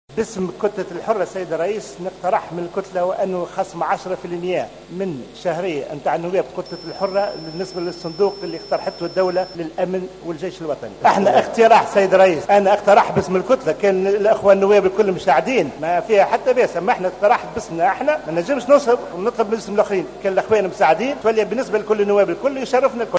Lors de son intervention au parlement aujourd'hui, Chérif a assuré que cette proposition concerne les députés de son bloc parlementaire, assurant que les portes sont ouvertes aux restes des députés souhaitant apporter leurs contributions.